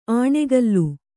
♪ āṇegallu